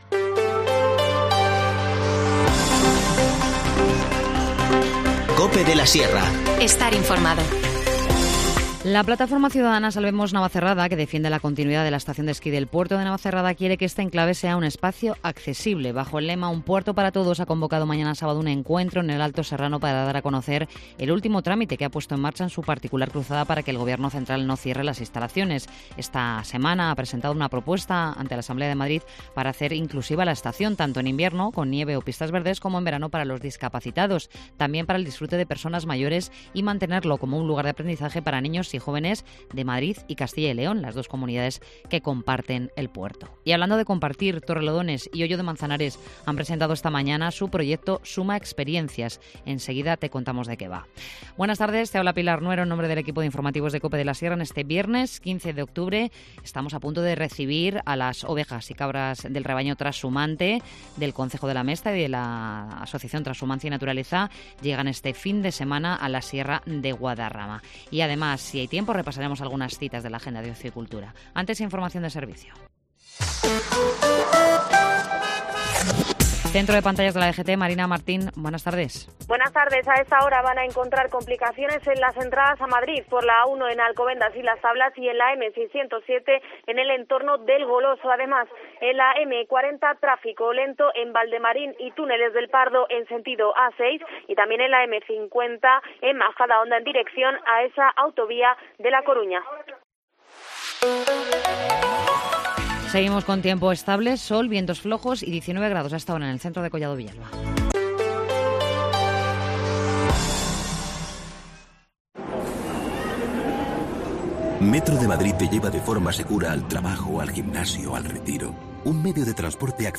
Informativo Mediodía 15 octubre